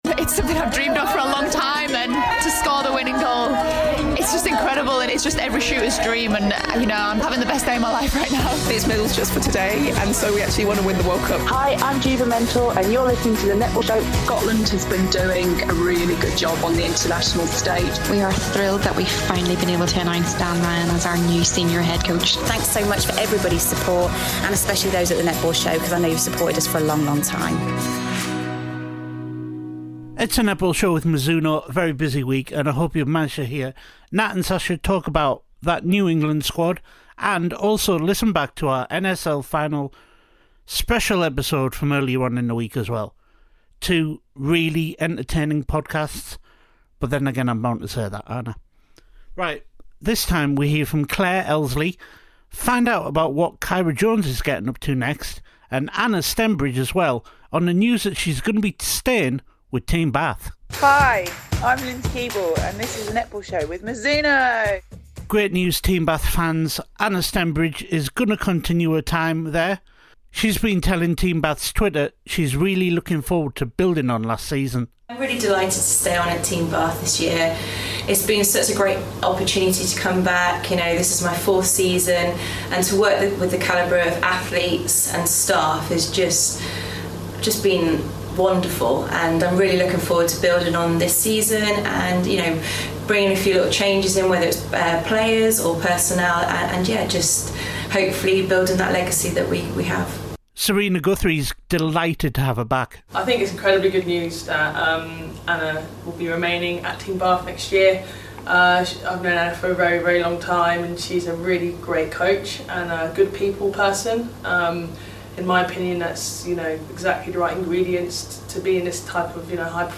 BIG INTERVIEW